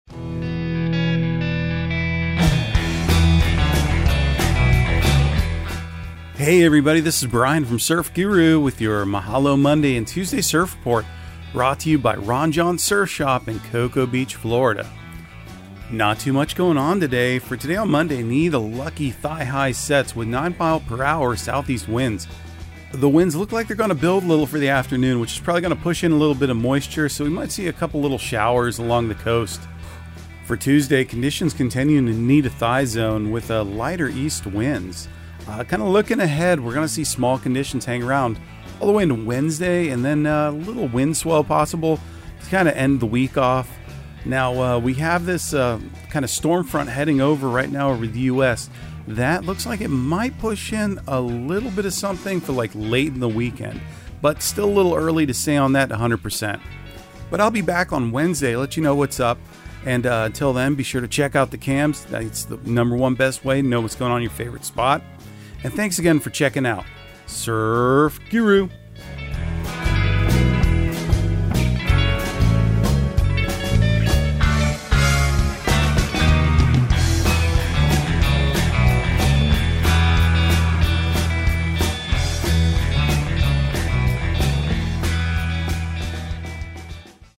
Surf Guru Surf Report and Forecast 04/03/2023 Audio surf report and surf forecast on April 03 for Central Florida and the Southeast.